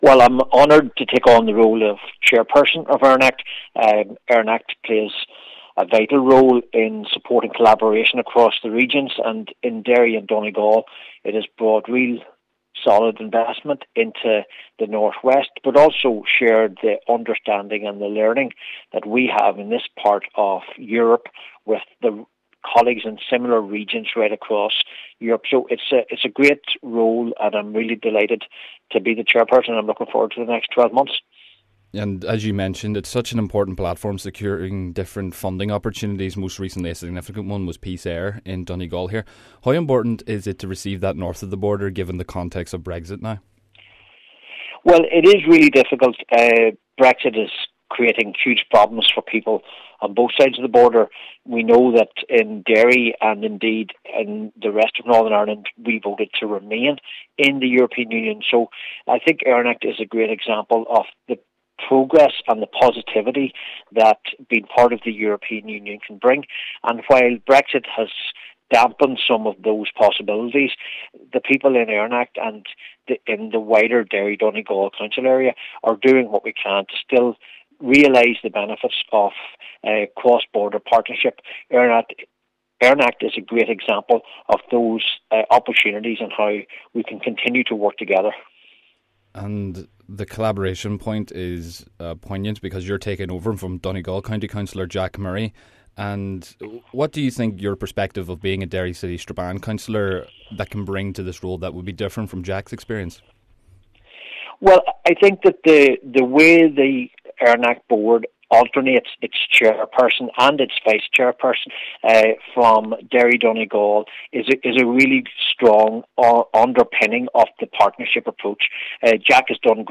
Cllr Reilly highlights the importance of the organisation: